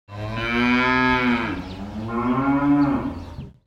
دانلود آهنگ مزرعه 16 از افکت صوتی طبیعت و محیط
دانلود صدای مزرعه 16 از ساعد نیوز با لینک مستقیم و کیفیت بالا
جلوه های صوتی